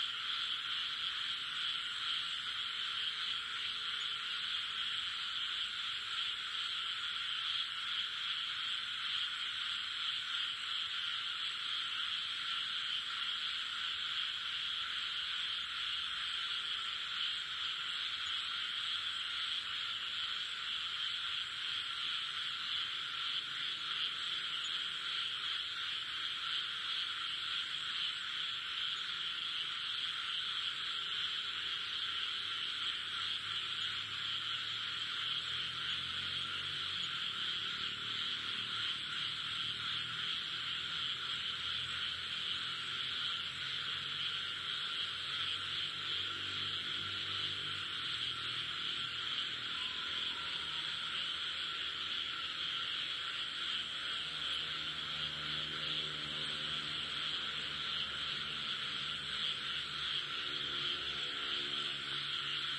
标签： soundscape ambience ambient frog ambiance nature fieldrecording
声道立体声